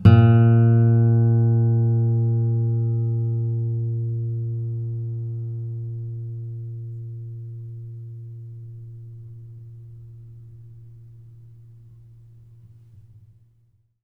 bass-08.wav